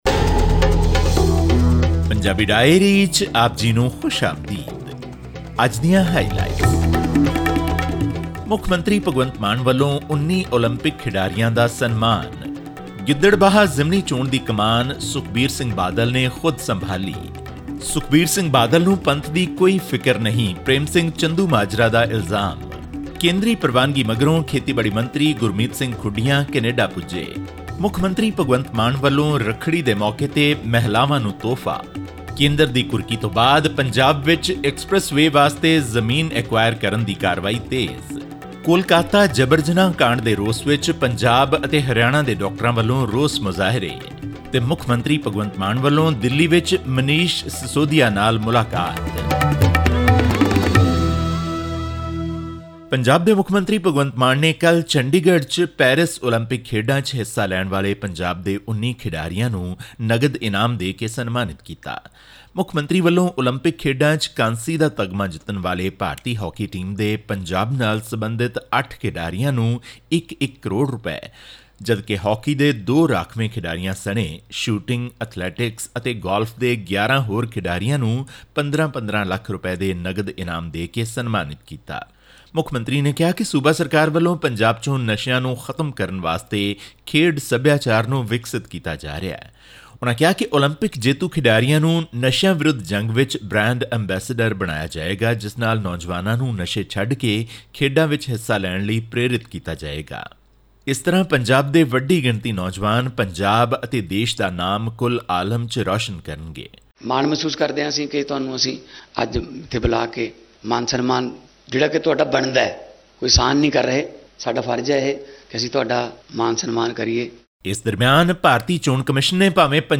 ਹੋਰ ਵੇਰਵੇ ਲਈ ਸੁਣੋ ਇਹ ਆਡੀਓ ਰਿਪੋਰਟ ..